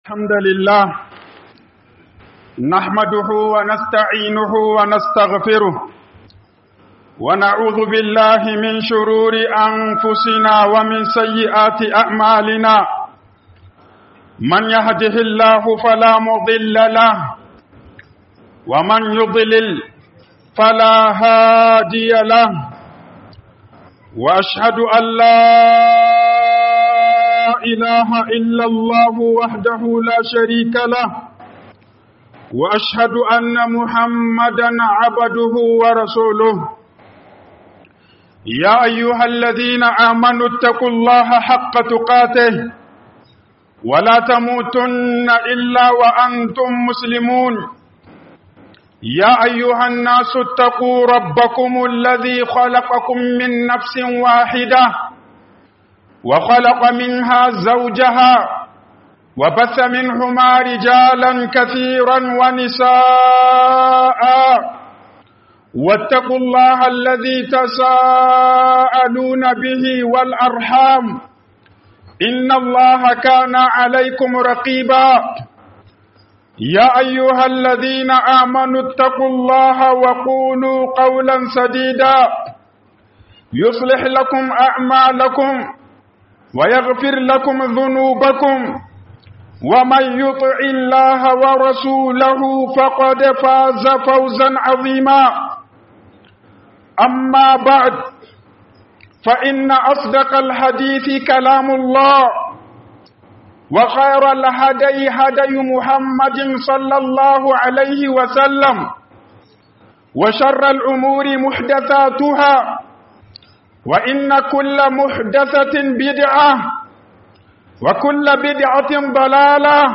KYAWAWAN DABI'U. - HUƊUBOBIN JUMA'A